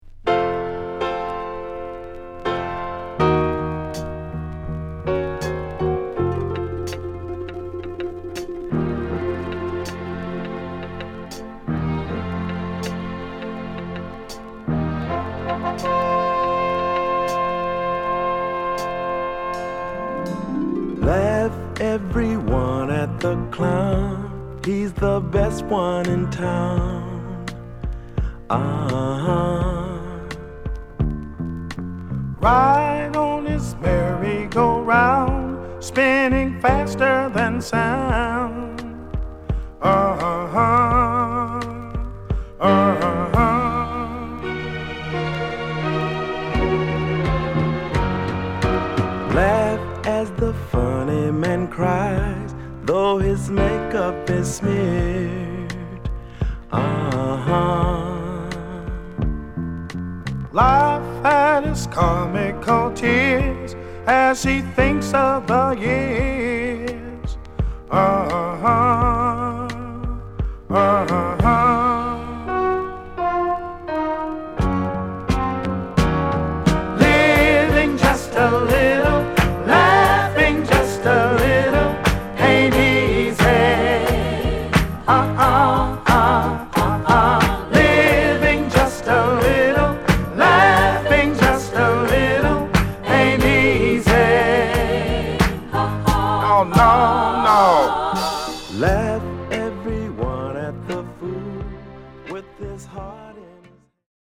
ストリングスの沁みるメロディをバックにしたこみ上げソウルを披露！
(Stereo)